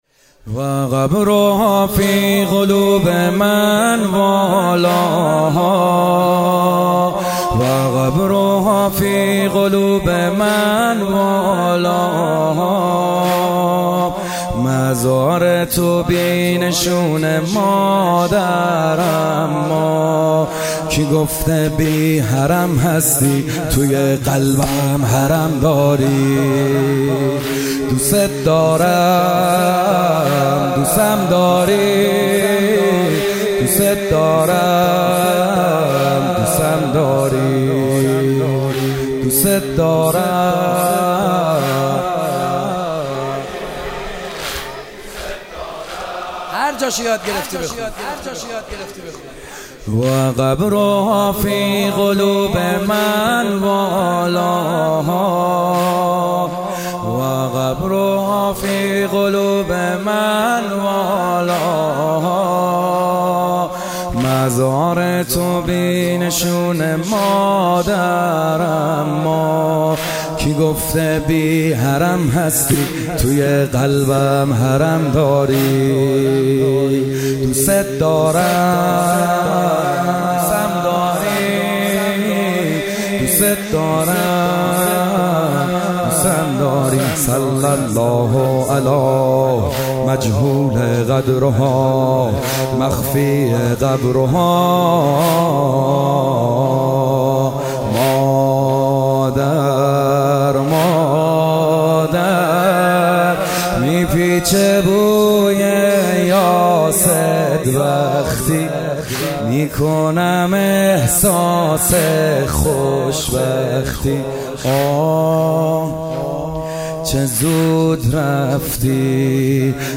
کربلایی محمدحسین حدادیان
فاطمیه 97 - روایت اول - شب دوم - واحد - و قبرها فی قلوب من والا